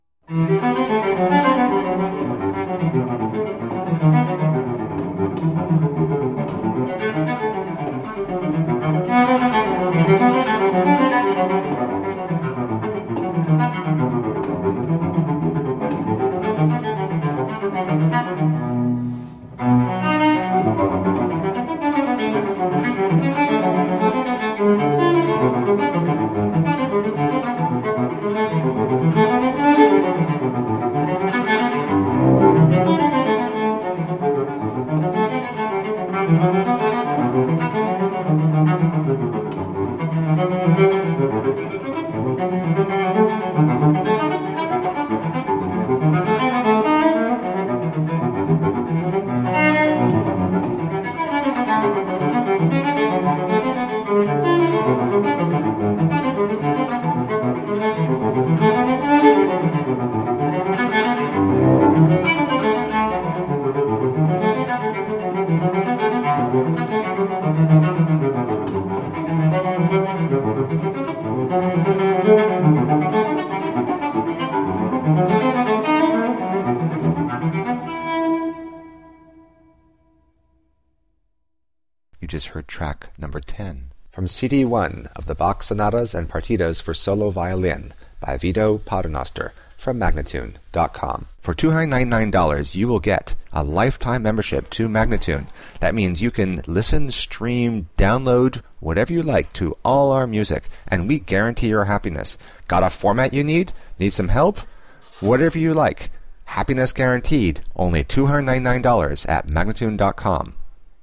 Classical, Baroque, Instrumental, Cello